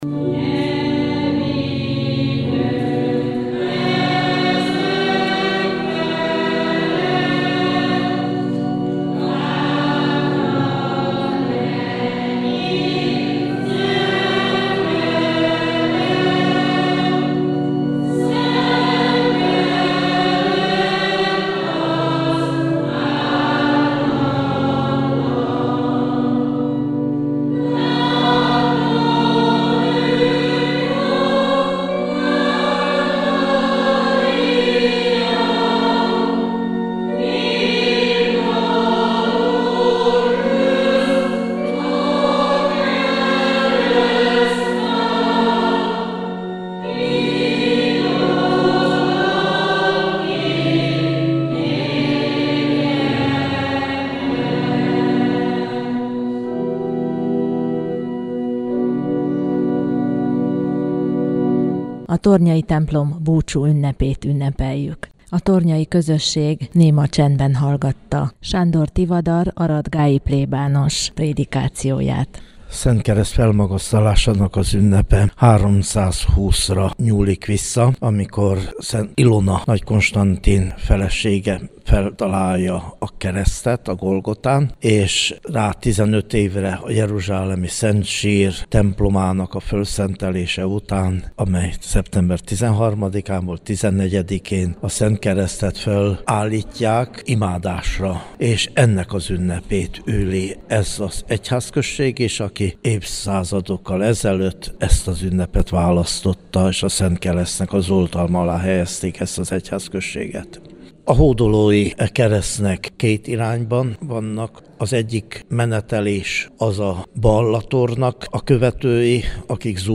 A tornyai búcsú a rádióban [AUDIÓ]
TORNYA_BUCSU_2013.mp3